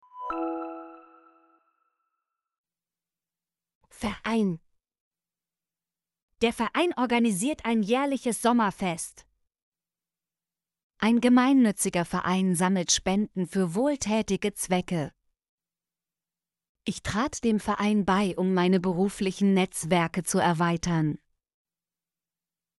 verein - Example Sentences & Pronunciation, German Frequency List